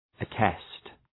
{ə’test}
attest.mp3